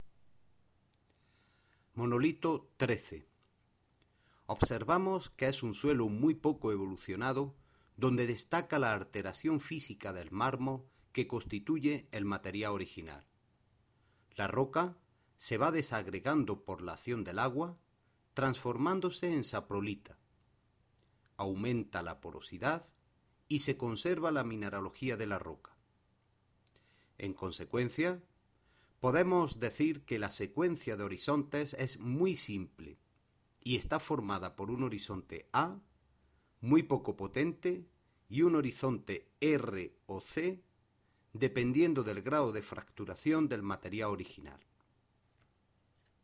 audio guía monolito 13